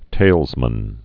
(tālzmən, tālēz-)